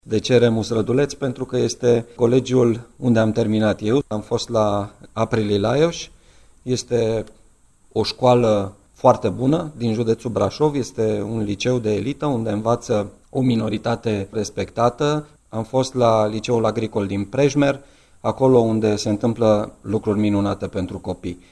Prefectul judeţului, Marian Rasaliu: